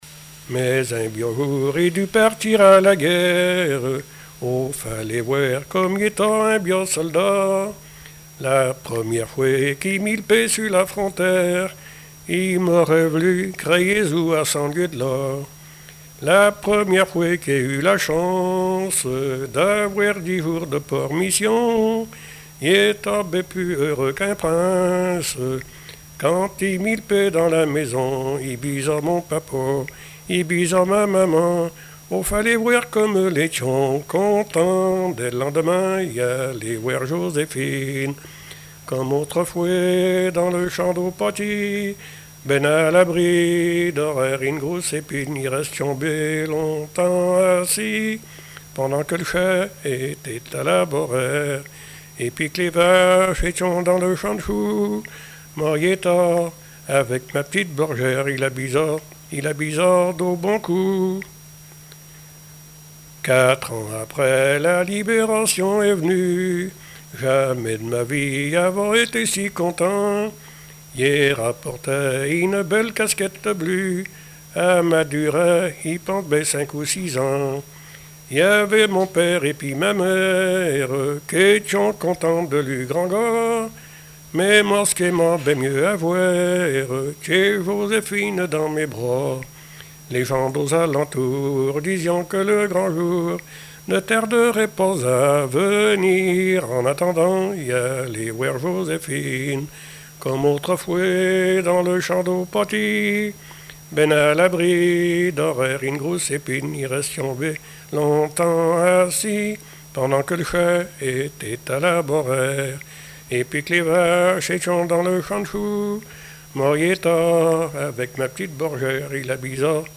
Patois local
Répertoire de chansons populaires et traditionnelles
Pièce musicale inédite